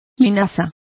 Complete with pronunciation of the translation of linseed.